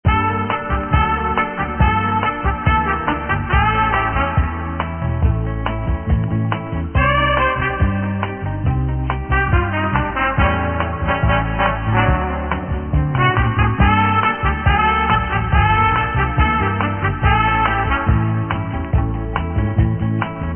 MPEG 22.05kHz ; 32 bit ; stereo 81kb losa kvaliteta